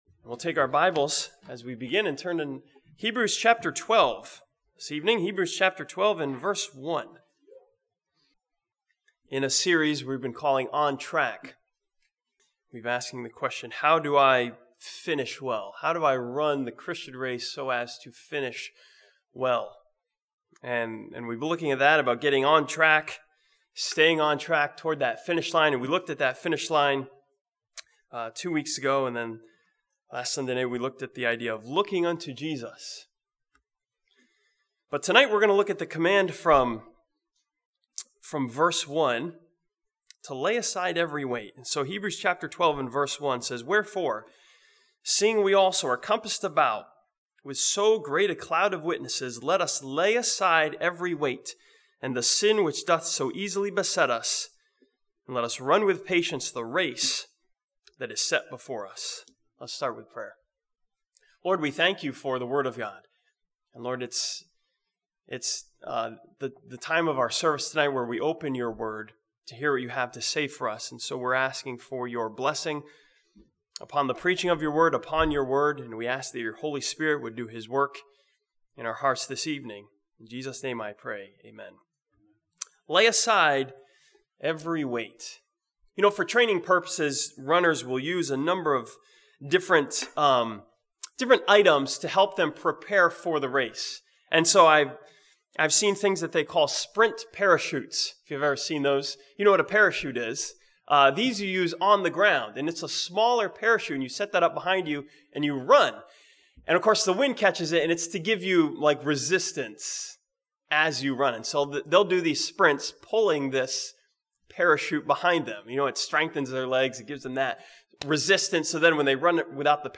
This sermon from Hebrews 12 continues the series On Track with the important admonition to lay aside every weight.